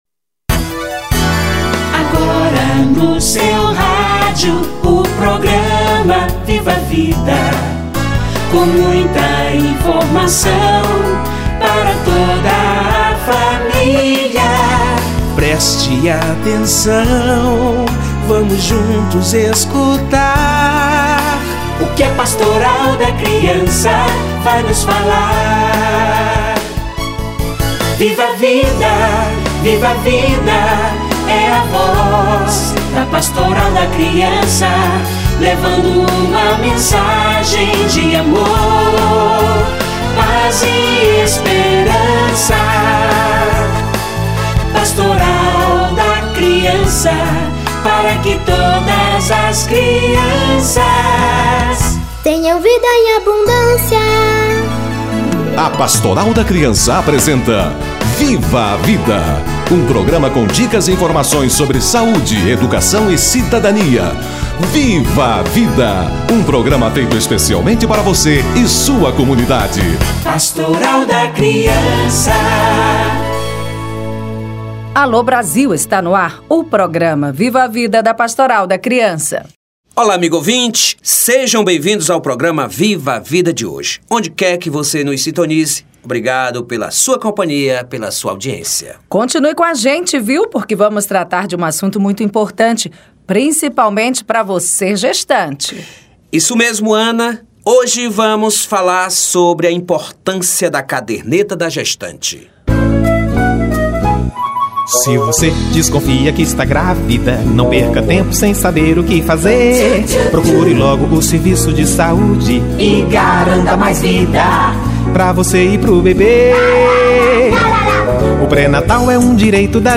Mutirão em busca da gestante - Entrevista